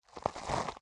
Minecraft Version Minecraft Version 1.21.5 Latest Release | Latest Snapshot 1.21.5 / assets / minecraft / sounds / block / chorus_flower / death3.ogg Compare With Compare With Latest Release | Latest Snapshot